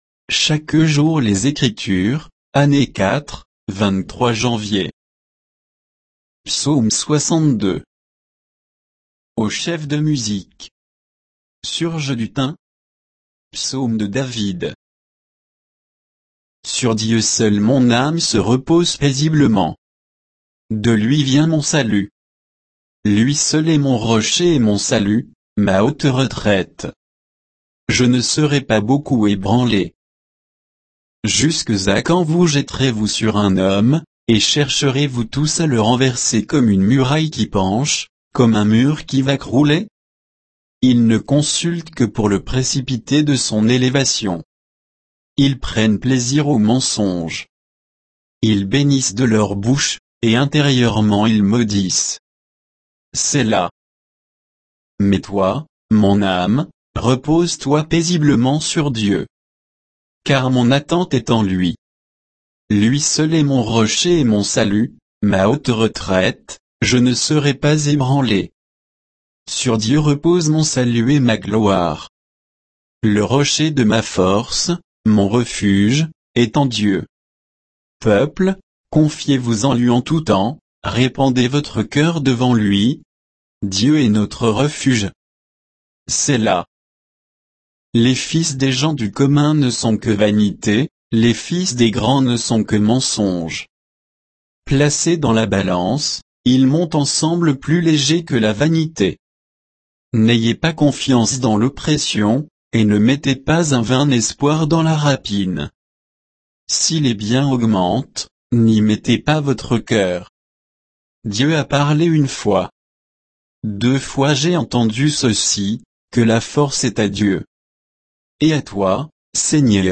Méditation quoditienne de Chaque jour les Écritures sur Psaume 62